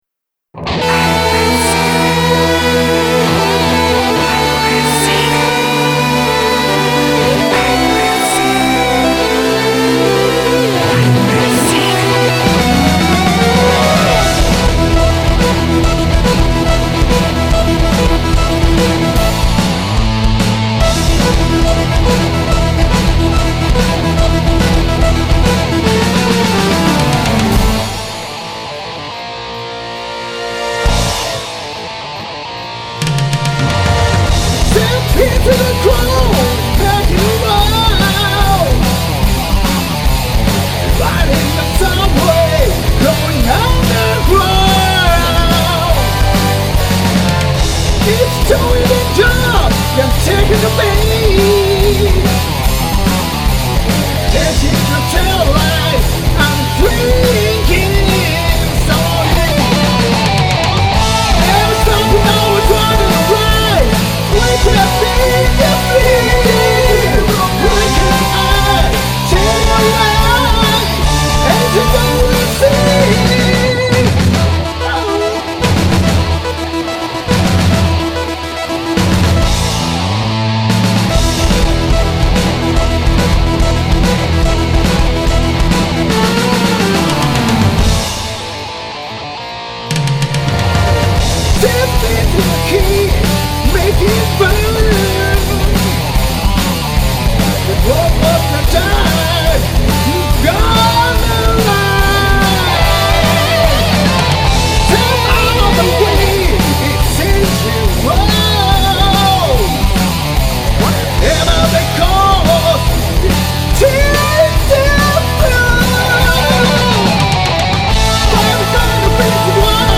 今回の多重録音は
ミクで知り合った素晴らしいプレイヤーさんと一緒に
演奏しました！（私はドラムとキーボード）
こんなに分厚い音源は初めてだったので